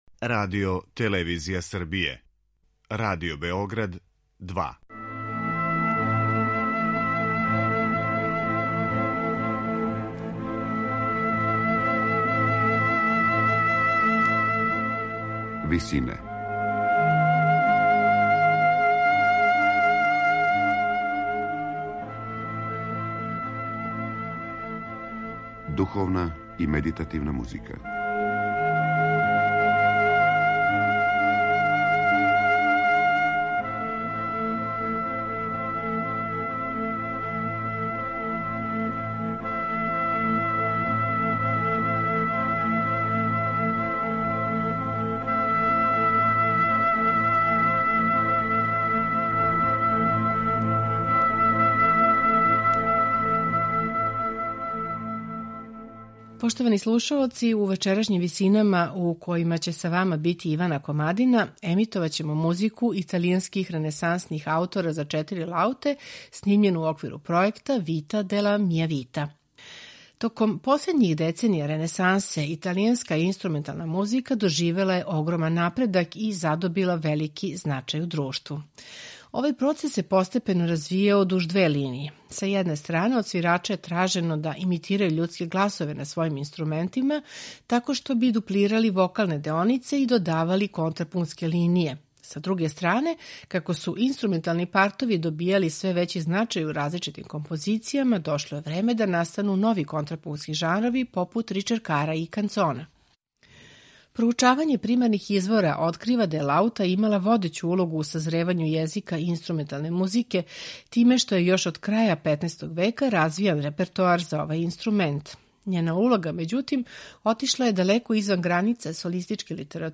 композиције италијанских ренесансних аутора за четири лауте